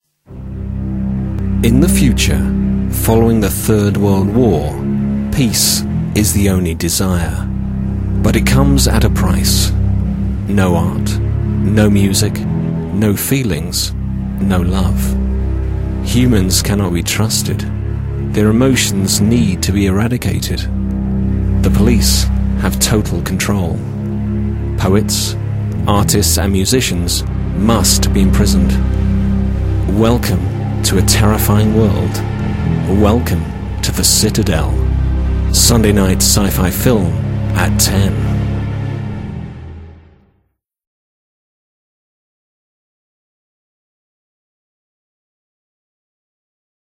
With twenty years of experience working in radio for the BBC and a rich, warm, cultured voice
SciFi TV Trail
02-Sci-Fi-TV-Trail.mp3